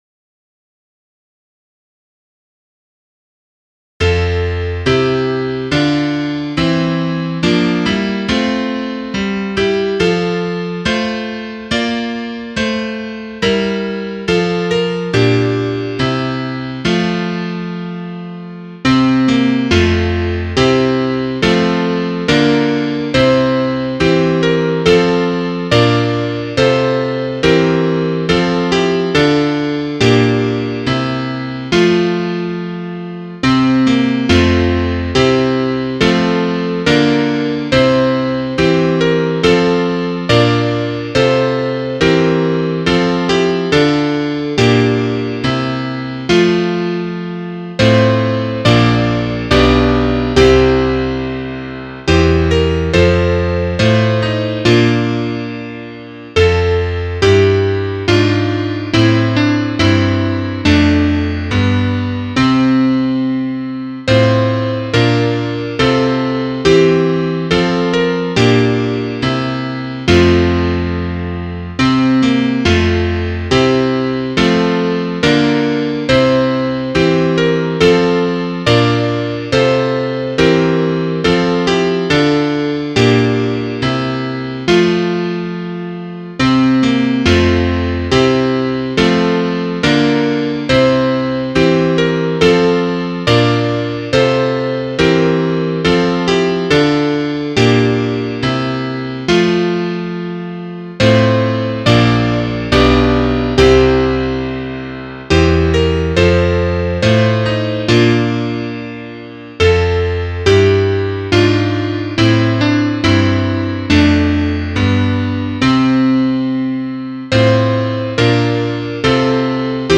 Trompete 2